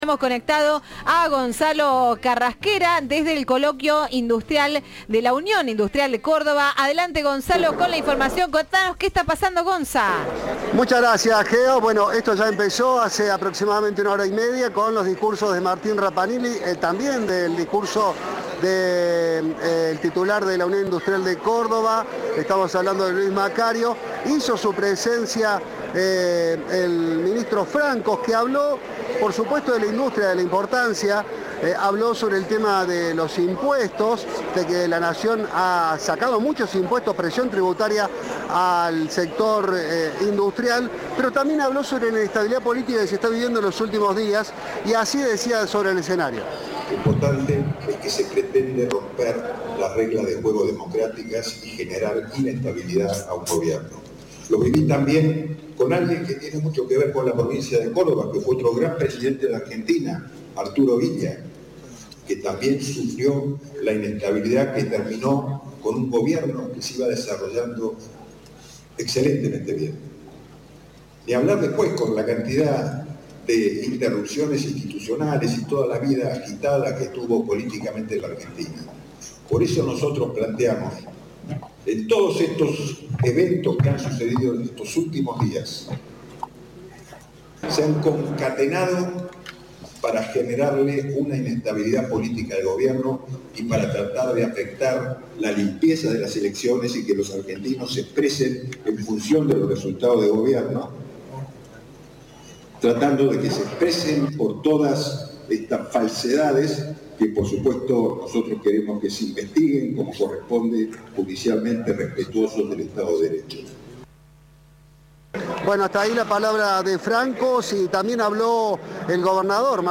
El gobernador de Córdoba habló desde la 17º edición del Coloquio Industrial de la UIC y se diferenció del gobierno de Milei.
FOTO: Martín Llaryora en el Coloquio de la UIC.